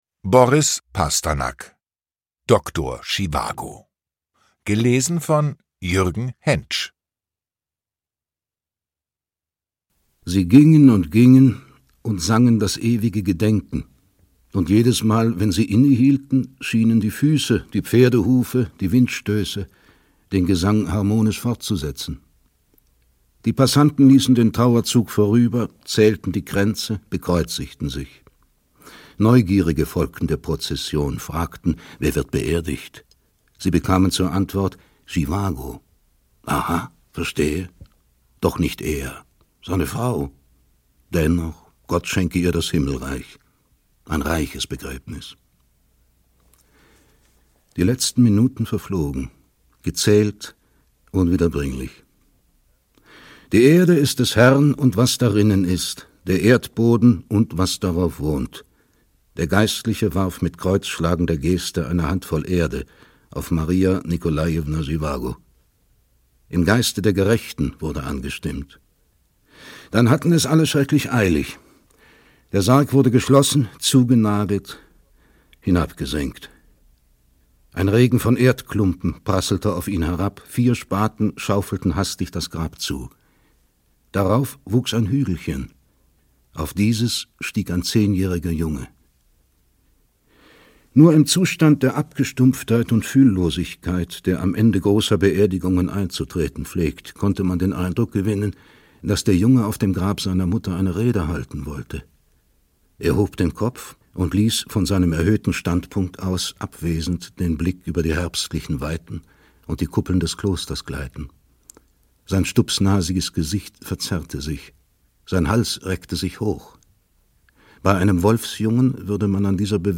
Lesung mit Jürgen Hentsch (2 mp3-CDs)
Jürgen Hentsch (Sprecher)
Jürgen Hentsch leiht dem Meisterwerk der russischen Literatur seine eindringliche Stimme.